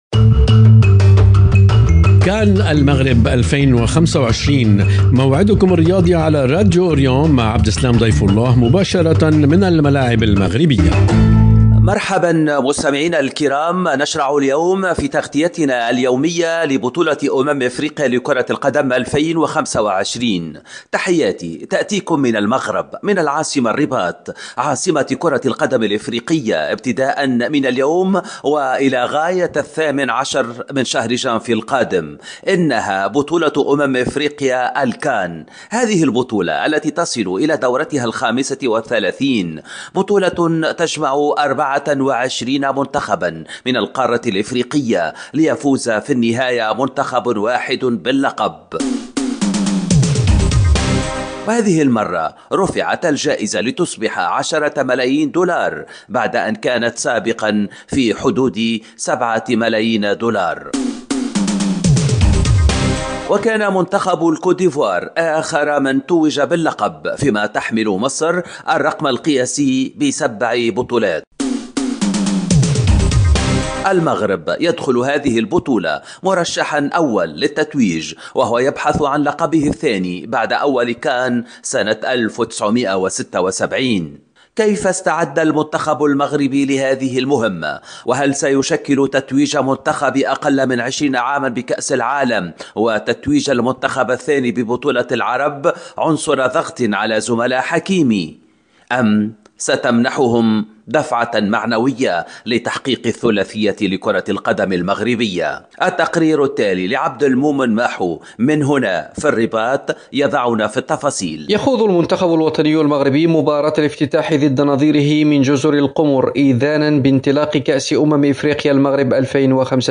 ومن الرباط أيضًا، ننقل لكم أبرز ما جاء في المؤتمر الصحفي لمدرب منتخب تونس، سامي الطرابلسي، الذي كان مرفوقًا بمتوسط الميدان إلياس السخيري، وذلك قبل انطلاق مشوار نسور قرطاج.